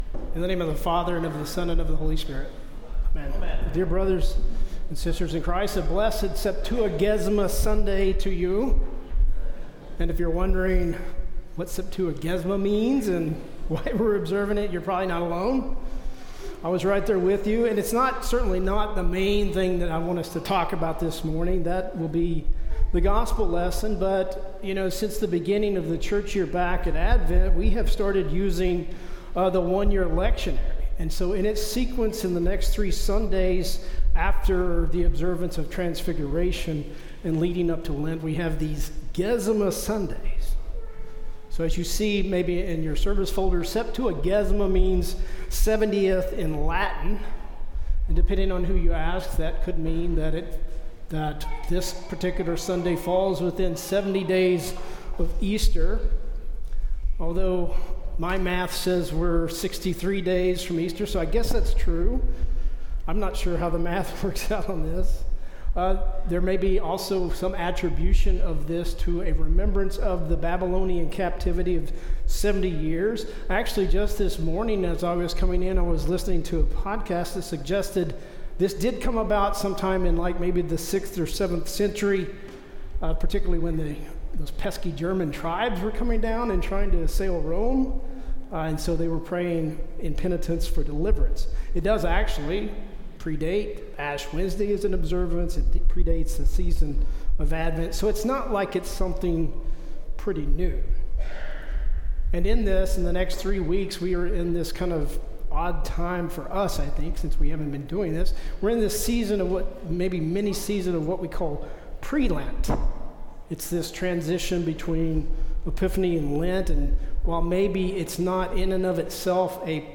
Sermon for Septuagesima